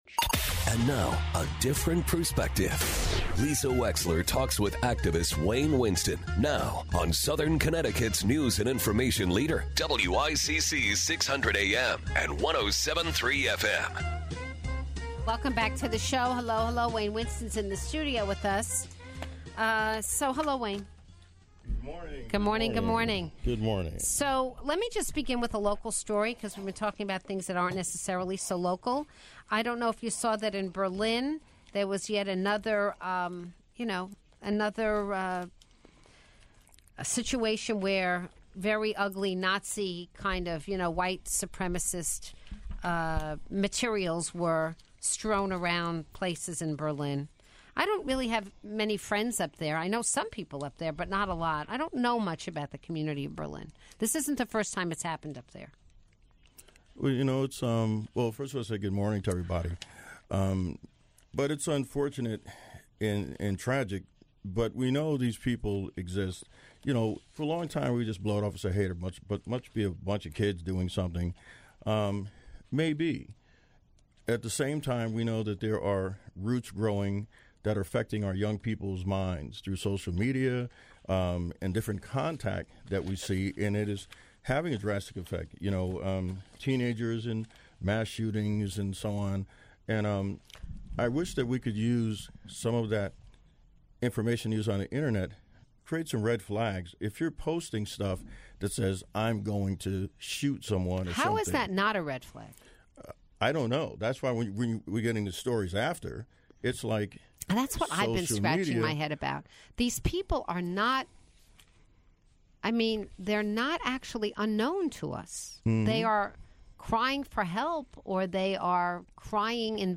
joined in studio